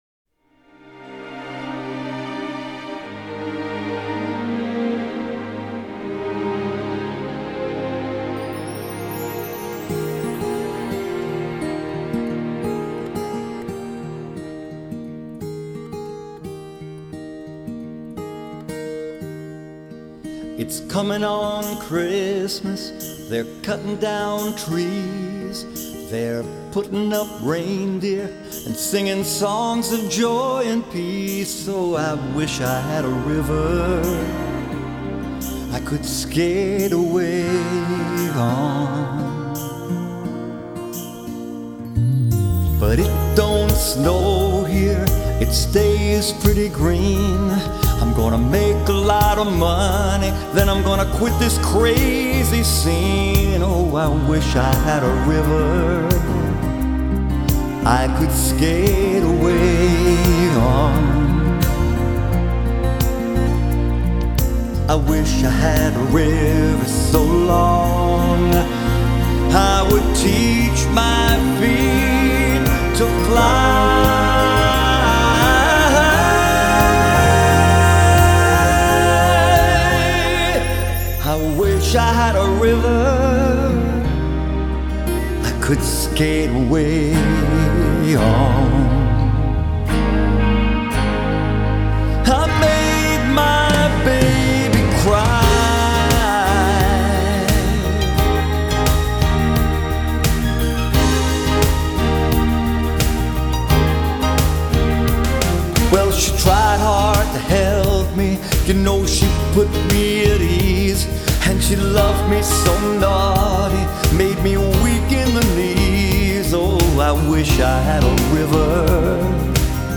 In fact, it just sounds kinda bad.